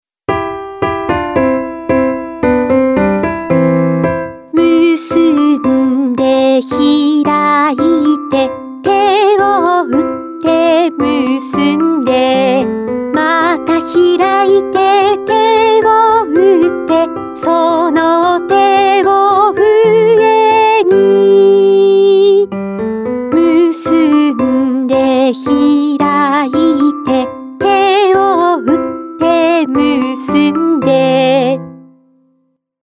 こどもに大人気のてあそびうた全20曲を収録した、音のでるえほん最新刊！
お手本のうたつき。